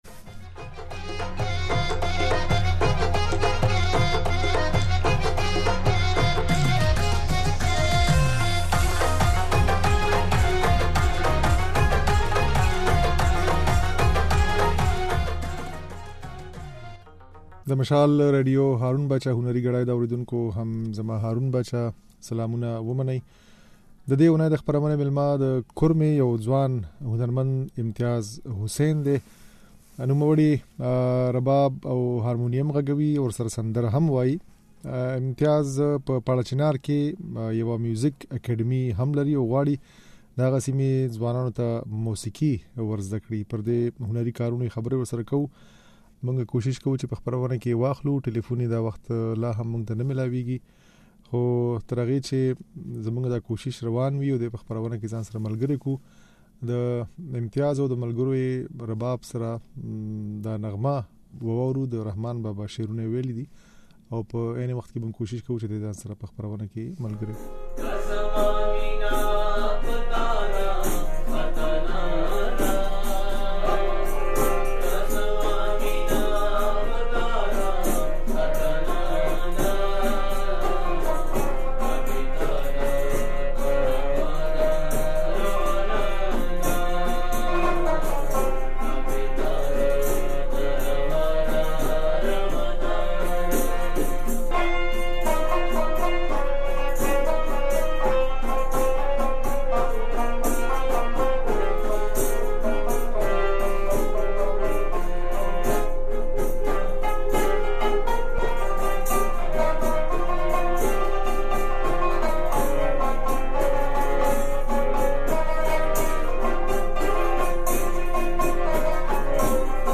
غږولې ځينې سندرې يې په خپرونه کې اورېدای شئ.